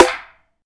SNARE.42.NEPT.wav